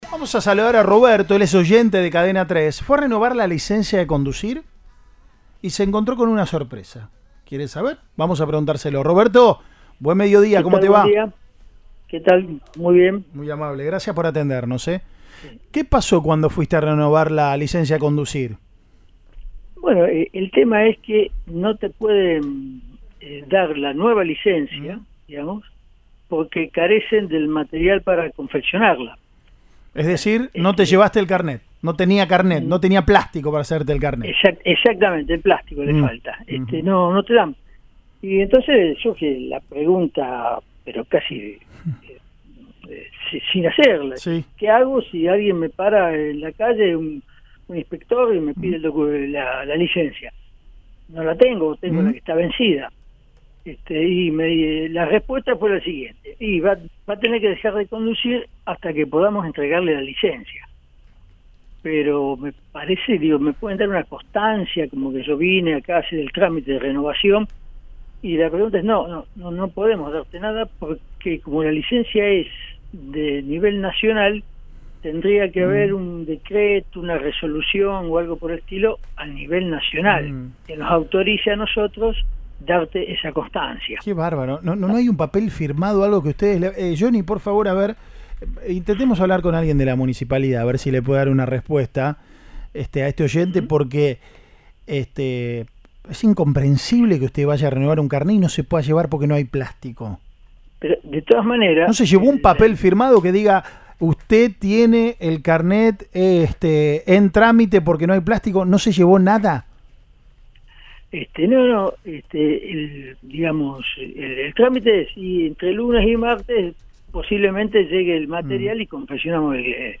En ese marcó, el director General de Tránsito Gustavo Adda, comentó en Siempre Juntos, por Cadena 3 Rosario, que se trata de insumos importados que vienen faltando desde fines de 2023 por la no entrega de la administración nacional a las distintas direcciones.